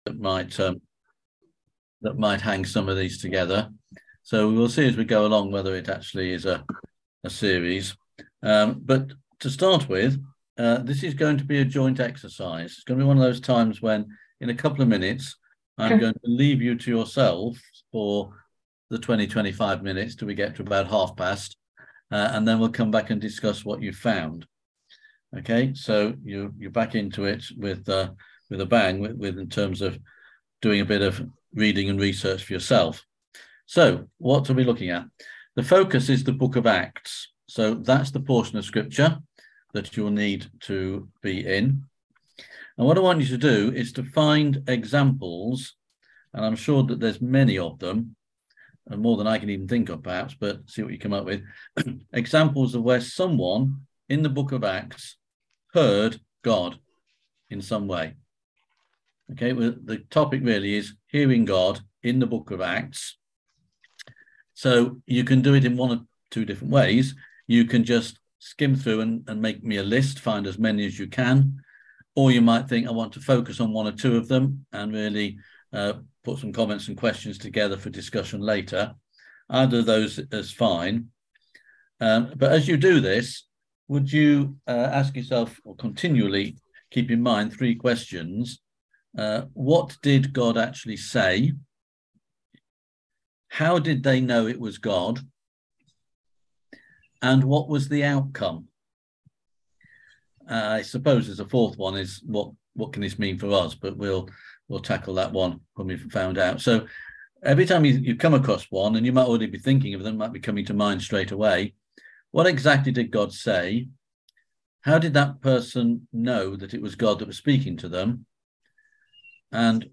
On January 26th at 7pm – 8:30pm on ZOOM